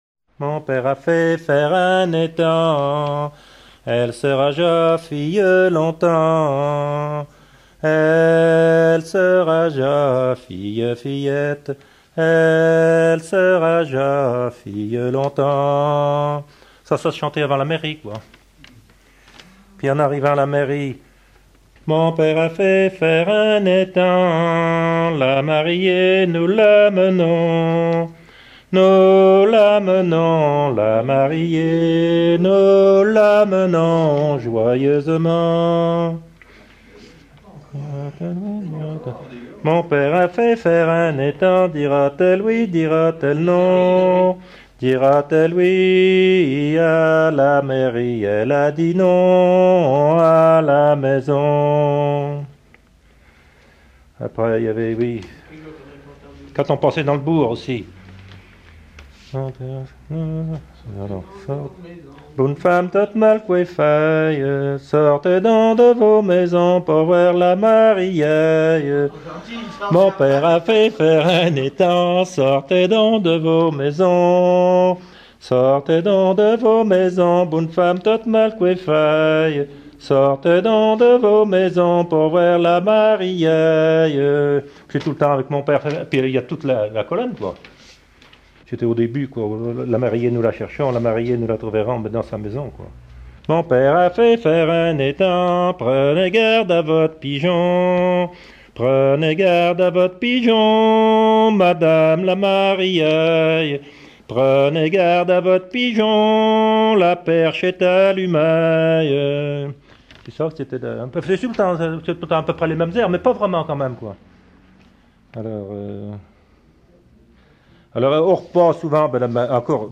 suite de refrains de noces
gestuel : à marcher
circonstance : fiançaille, noce
Genre laisse